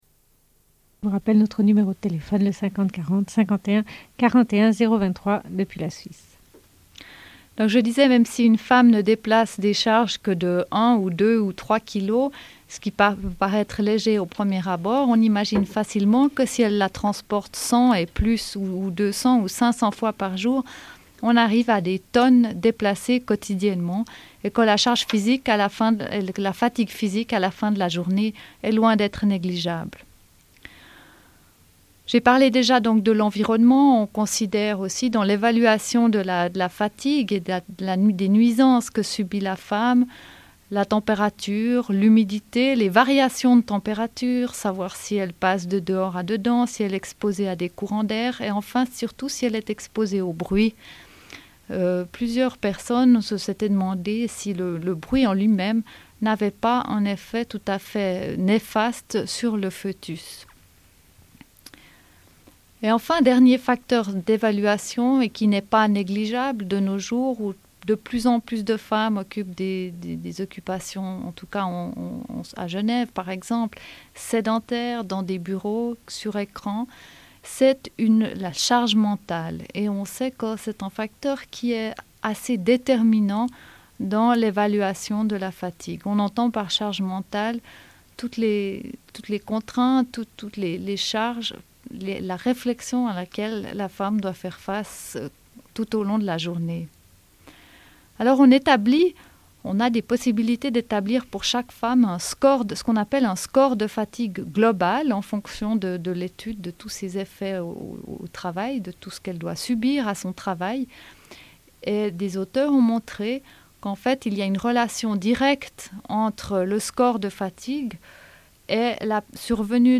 Une cassette audio, face B31:38
Radio Enregistrement sonore